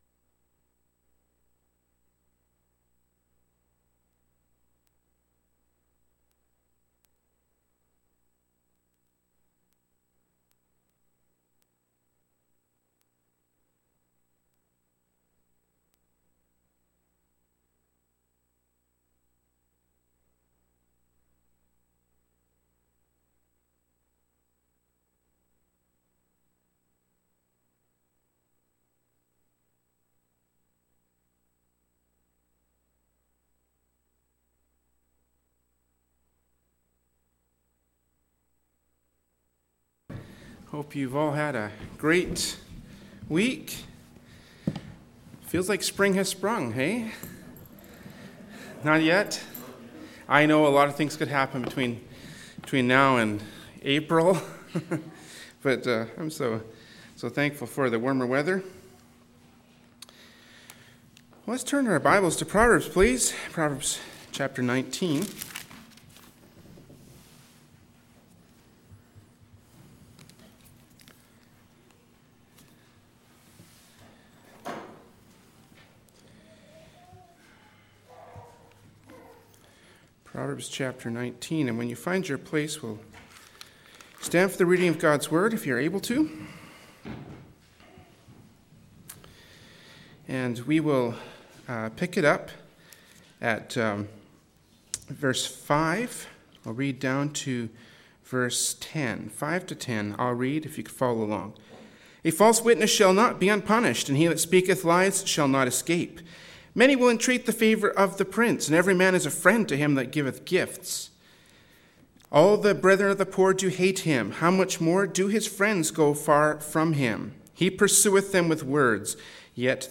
“Proverbs 19:5-10” from Sunday School Service by Berean Baptist Church.